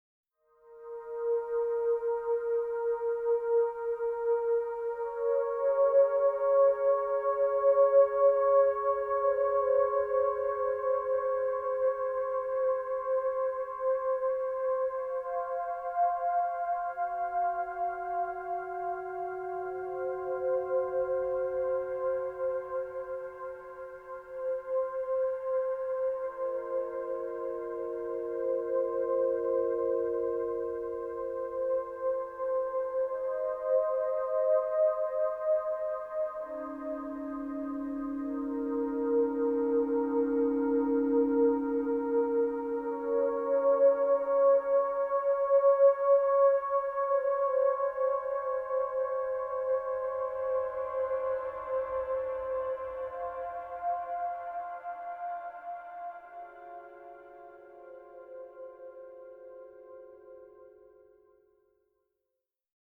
singer-songwriter
playing classical guitar and acoustic bass herself
string quartet